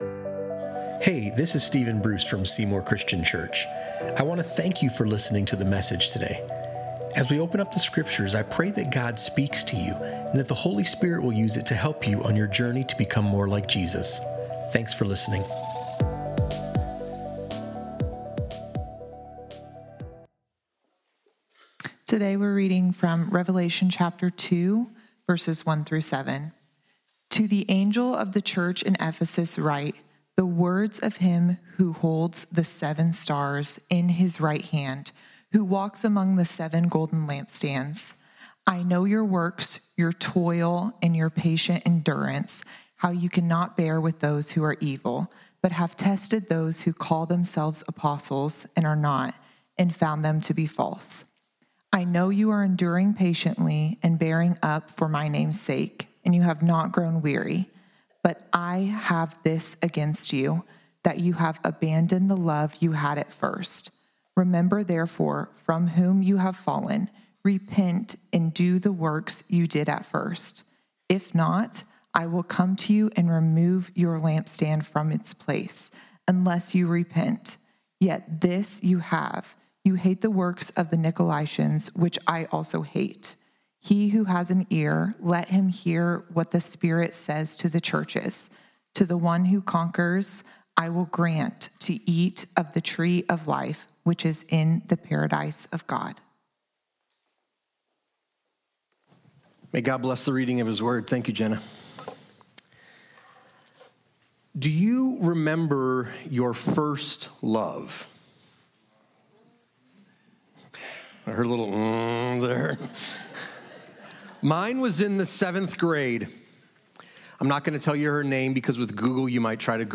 Revelation 2:1–7 — Remember Your First Love | Sermon on the Church in Ephesus